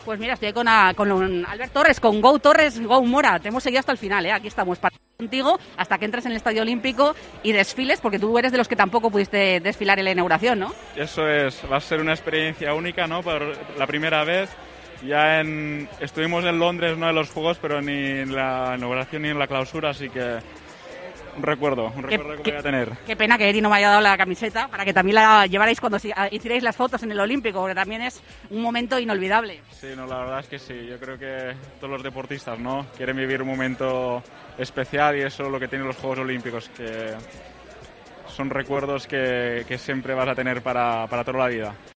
El ciclista habló en COPE durante la Ceremonia de Clausura y reconoció que ha vivido "un momento único" en Tokio.